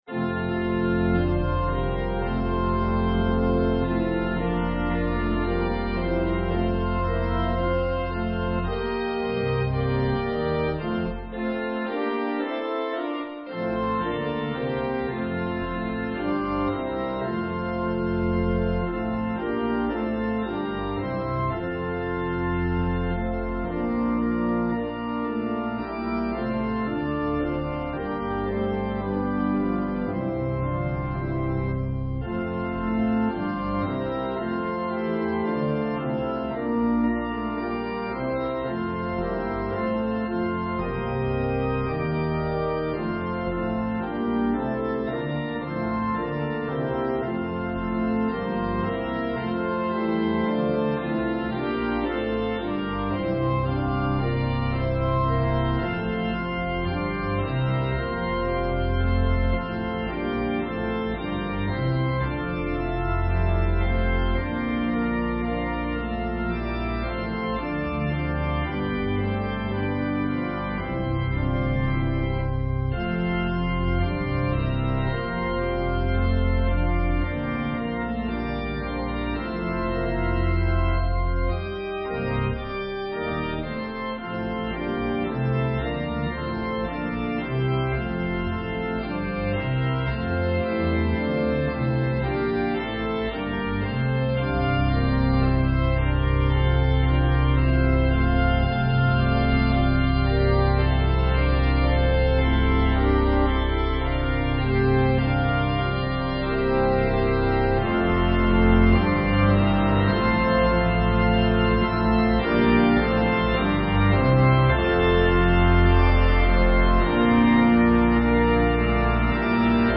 An organ solo version
Voicing/Instrumentation: Organ/Organ Accompaniment